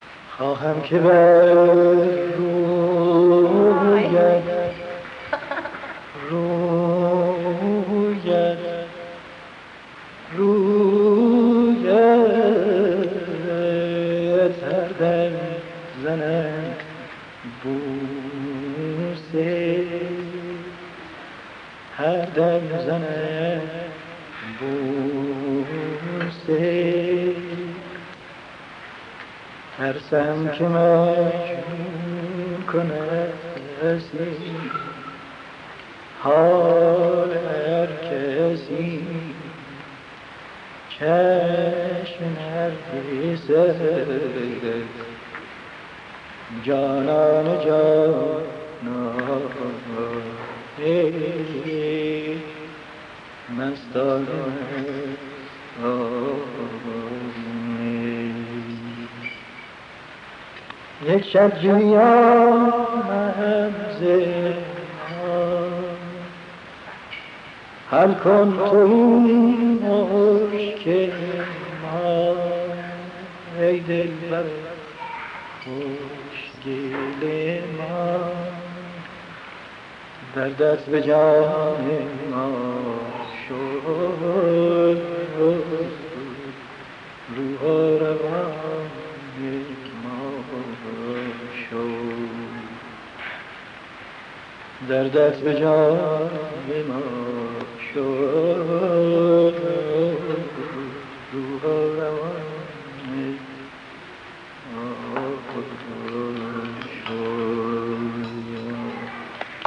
اجرای خصوصی تصنیف چشم نرگس با صدای استاد شجریان بدون ساز + صوت
تصنیف چشم نرگس یکی از درخشان‌ترین تصنیف‌های موسیقی ایرانی است که توسط خوانندگان فراوانی اجرا شده است. بدون شک یکی از دلنشین ترین این اجراها، اجرای خصوصی و بدون ساز محمدرضا شجریان از این تصنیف است که در کمال صمیمیت و فروتنی خوانده شده است.
سلام نو-سرویس فرهنک و هنر: محمدرضا شجریان هنرمندی است که نمی‌توان از کارها و اجراهای او لذت نبرد، اما باید پذیرفت که در اجراهای خصوصی و بدون ساز او، هرچند از تمام ظرفیت تکنیکی صدای خود بهره نبرده است، صمیمیت و فروتنی‌ای نهفته است که گونه‌ای دیگر از لذت هنری را برای مخاطب به ارمغان می‌آورد.